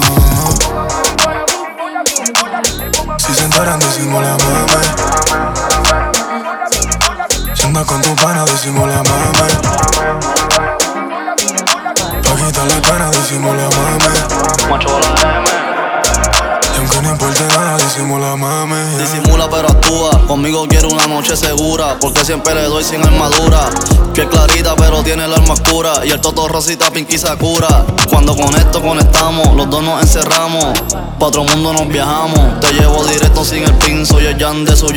Жанр: Хип-Хоп / Рэп / Латино